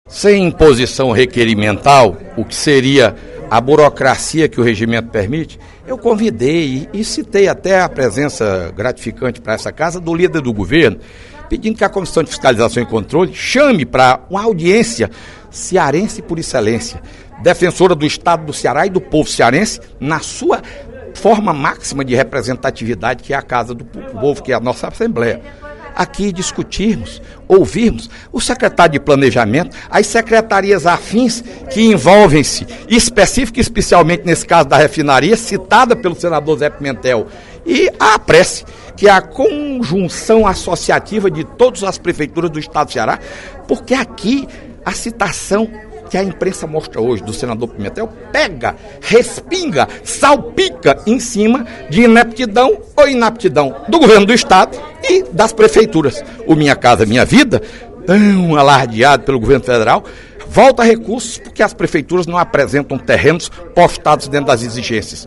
O deputado Fernando Hugo (PSDB) chamou atenção, na sessão plenária desta quarta-feira (15/02), para matéria publicada hoje no jornal Diário do Nordeste, sobre os recursos do Governo Federal que deveriam ser aplicados na construção de moradias populares do Programa de Aceleração do Crescimento (PAC) e nas obras da refinaria Premium, no Pecém, no Estado do Ceará.